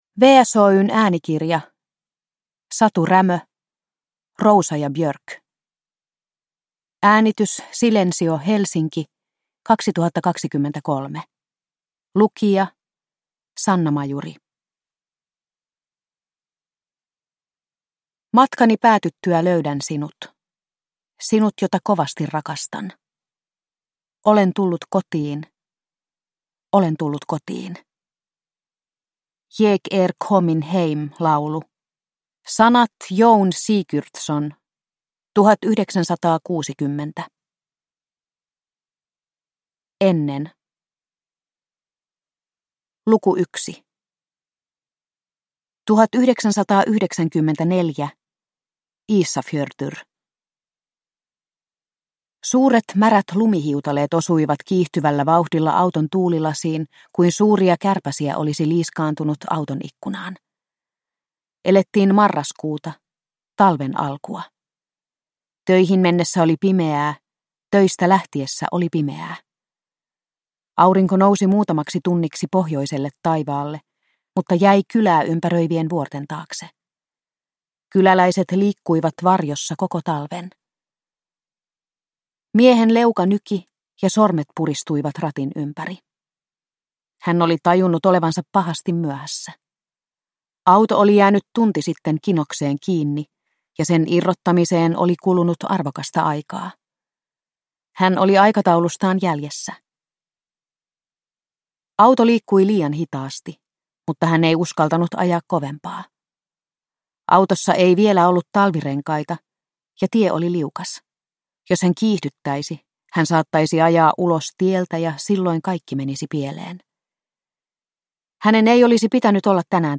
Rósa & Björk – Ljudbok – Laddas ner